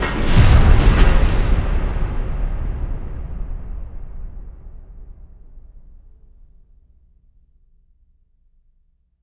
LC IMP SLAM 3A.WAV